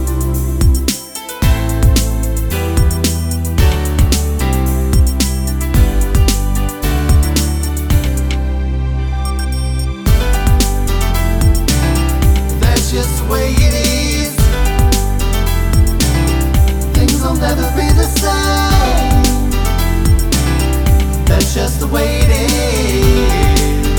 no Backing Vocals R'n'B / Hip Hop 4:30 Buy £1.50